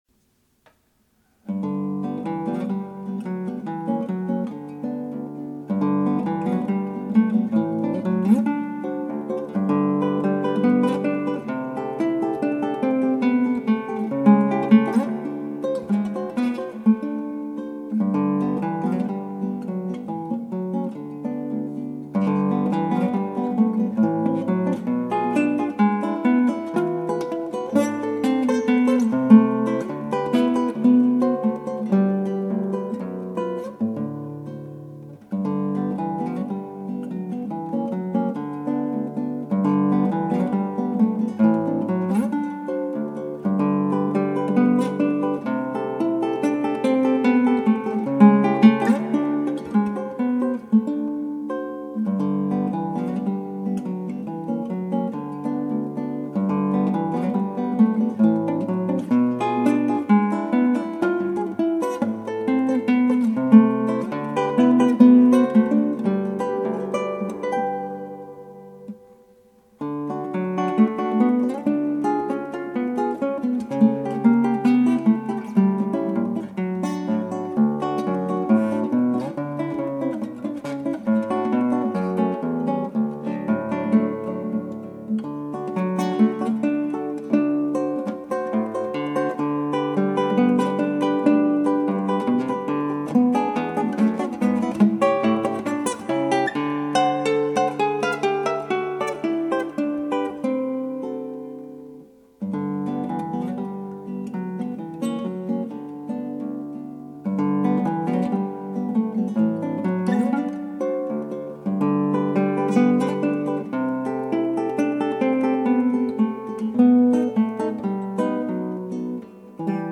クラシックギター ロマンチスト・バリオス - 「Romanza-No1」
ギターの自演をストリーミングで提供
この曲もそんなバリオスのロマンチック炸裂の曲なんですが、なんと言うか散文的な曲でまとまりのない演奏になりがちです。